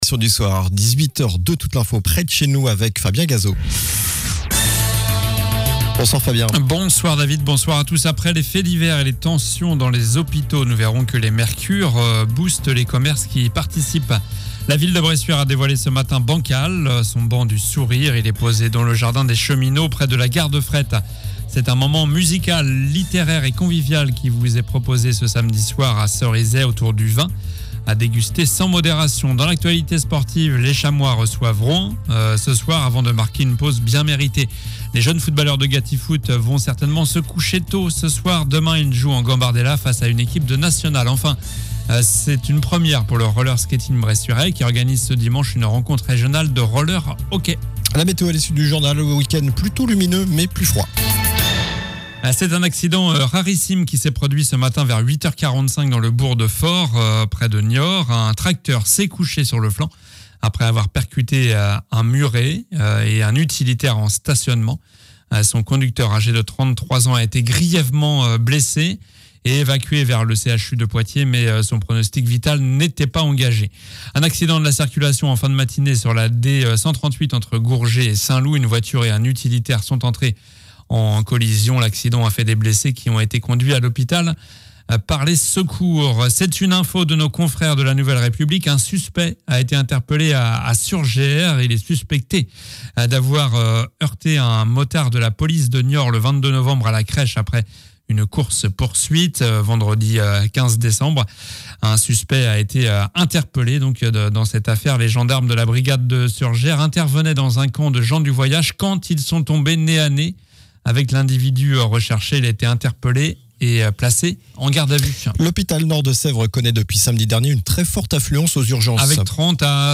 Journal du vendredi 15 décembre (soir)